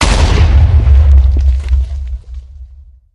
minecraft_explosion.wav